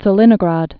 (tsə-lĭnə-gräd)